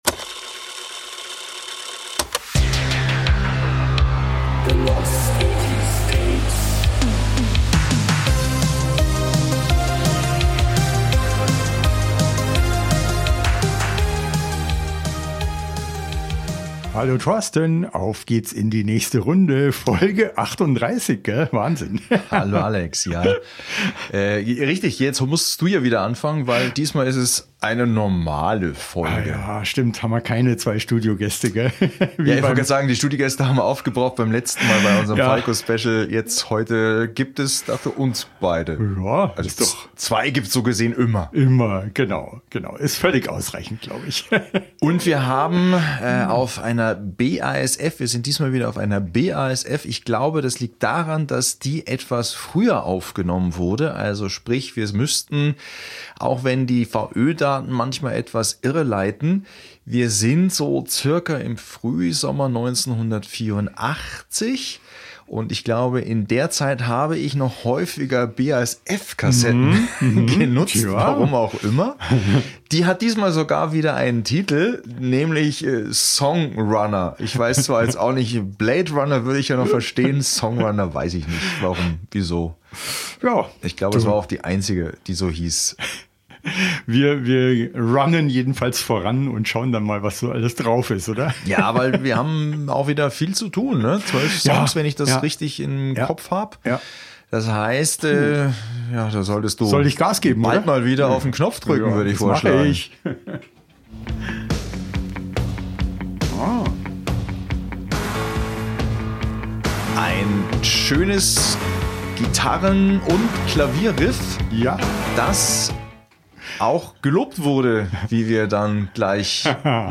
Weiteren Disco-Beat - allerdings mit weniger Gitarre